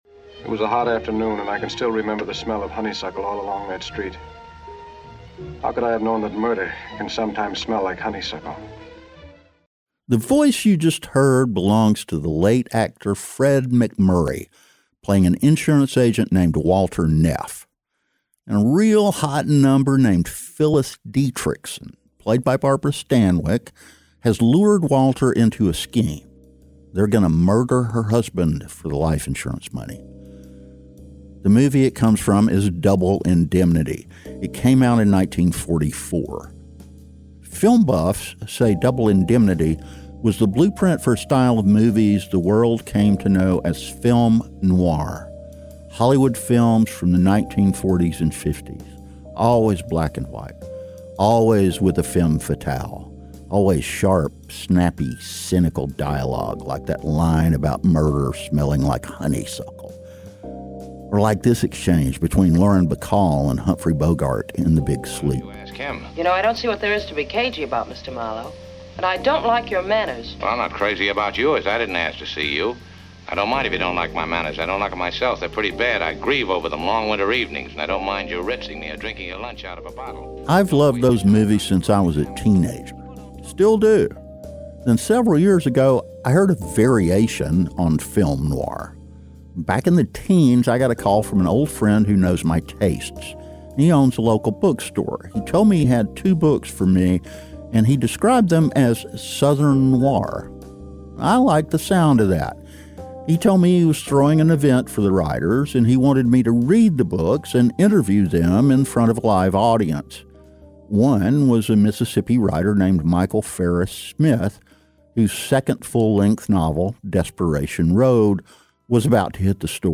explores the evolution of Southern fiction through conversations with acclaimed authors